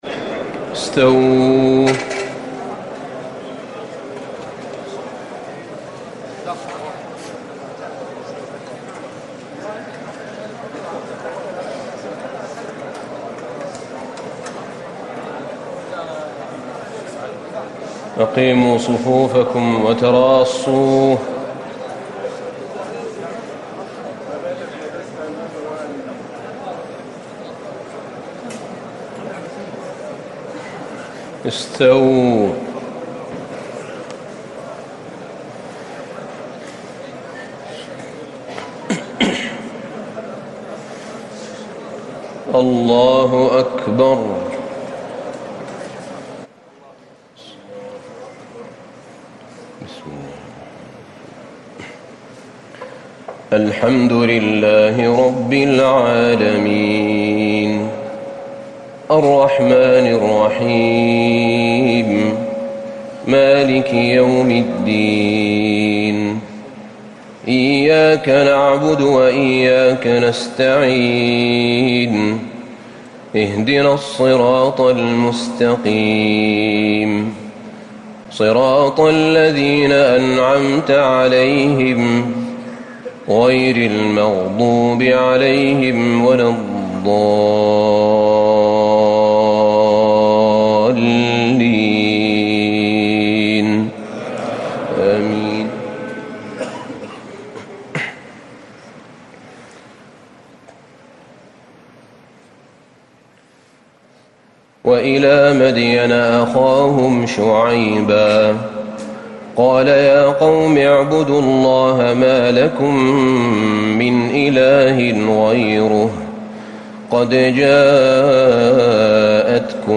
تهجد ٢٩ رمضان ١٤٤٠ من سورة الأعراف ٨٥ - ١٨٨ > تراويح الحرم النبوي عام 1440 🕌 > التراويح - تلاوات الحرمين